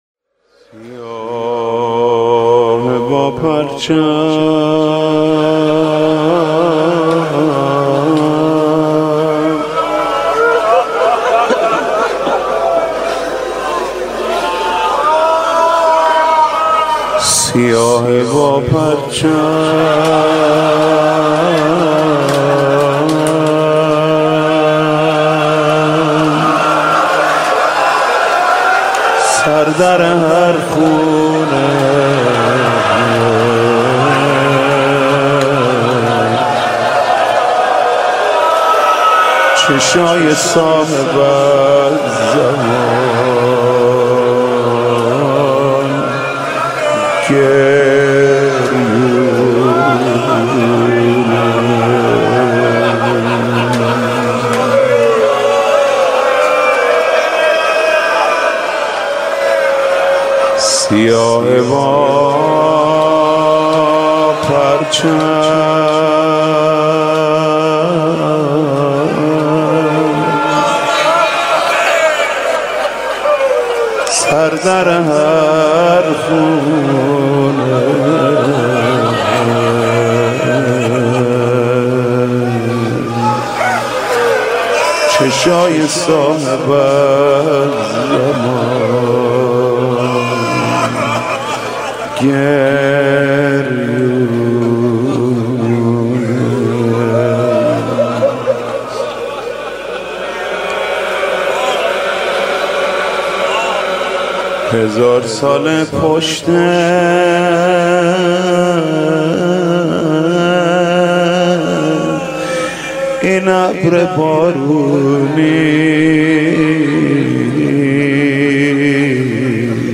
شب اول محرم الحرام 1394 | هیات رایه العباس | حاج محمود کریمی
سیاهه با پرچم سر در هر خونه | زمزمه | ورود به محرم